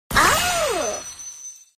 Notifikasi Emote Mobile Legends Alice Awww
Kategori: Nada dering
nada-notifikasi-emote-mobile-legends-alice-awww-id-www_tiengdong_com.mp3